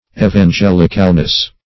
Evangelicalness \E`van*gel"ic*al*ness\, n. State of being evangelical.
evangelicalness.mp3